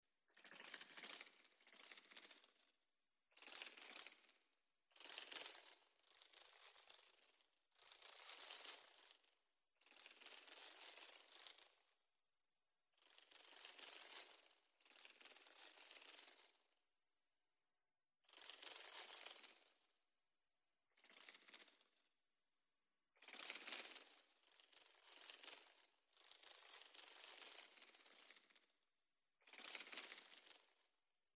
Звуки мотылька
На этой странице собраны уникальные звуки мотыльков: от легкого шелеста крыльев до едва уловимого трепета в ночи.
Звук крыльев бабочки